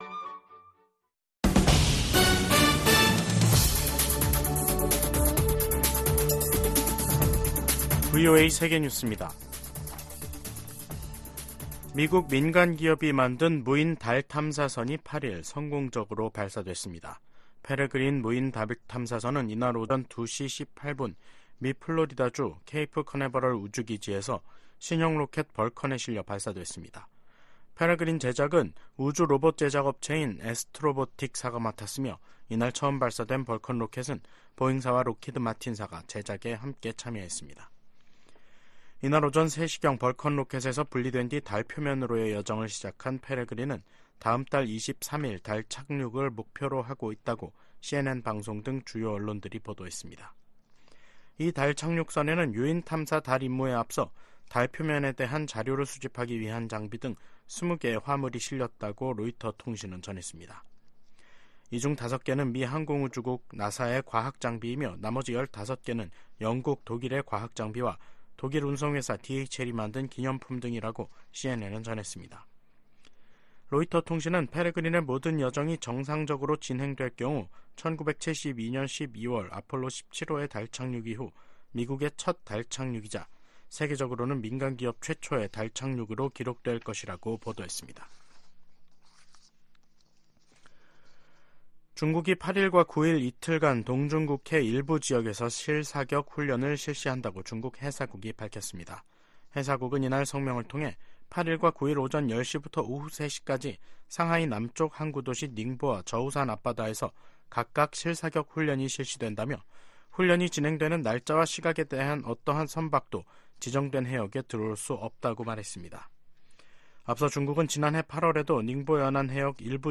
VOA 한국어 간판 뉴스 프로그램 '뉴스 투데이', 2024년 1월 8일 3부 방송입니다. 북한 군이 한국의 서북도서 인근에서 포 사격을 실시하자 한국 군도 해당 구역 군사훈련을 재개하기로 했습니다. 미 국무부는 북한의 서해 해상 사격에 도발 자제와 외교적 해결을 촉구했습니다. 미국 정부는 팔레스타인 무장정파 하마스가 북한 로켓 부품을 이용해 신무기를 만들고 있는 것과 관련해, 북한은 오래 전부터 중동 지역에 무기를 판매해 오고 있다고 밝혔습니다.